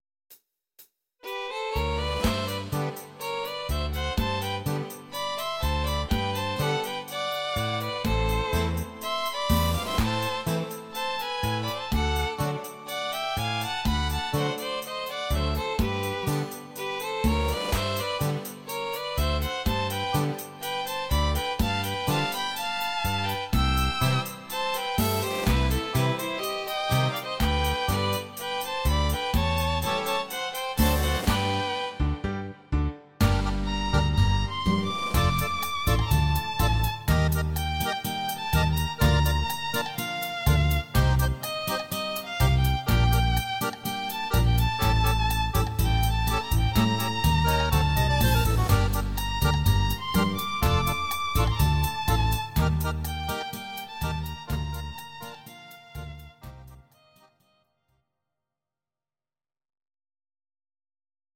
These are MP3 versions of our MIDI file catalogue.
Please note: no vocals and no karaoke included.
Argentinian tango